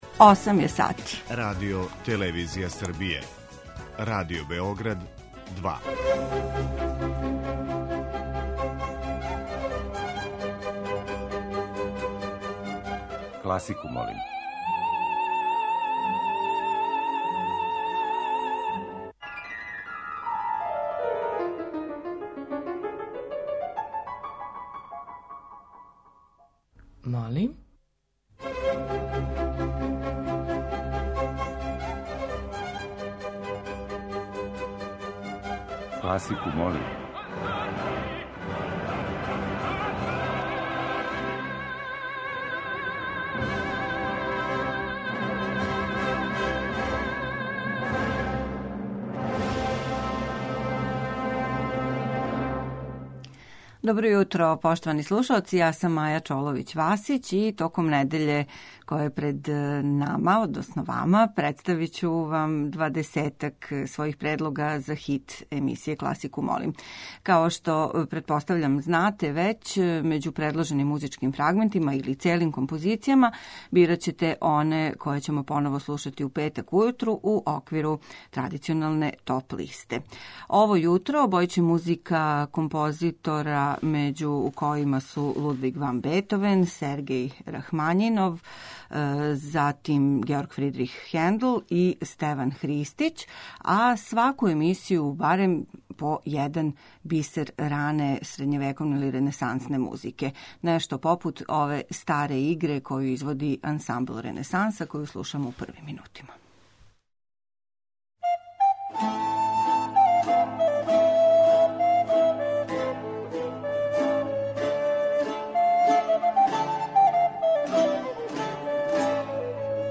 Средњевековне и ренесансне игре
Још један избор најразноврснијих музичких фрагмената за хит недеље биће обједињен средњовековним и ренесансним играма.